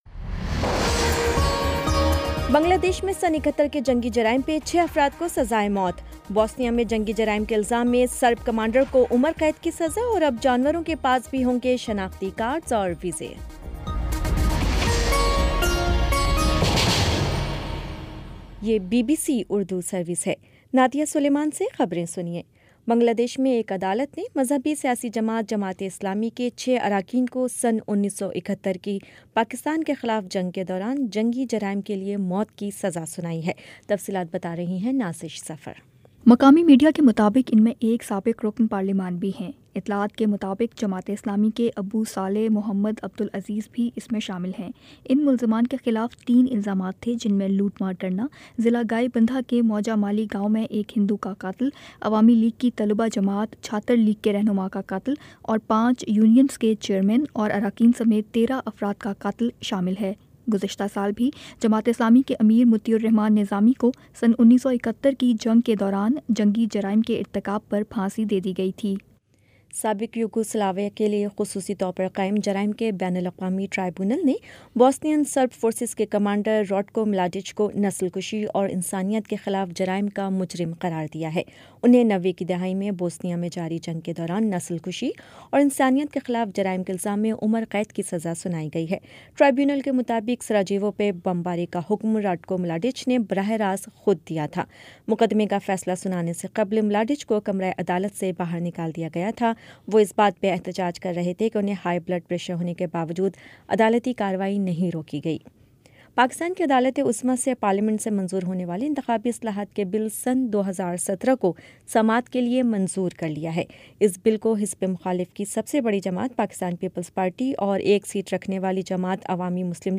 نومبر 22 : شام پانچ بجے کا نیوز بُلیٹن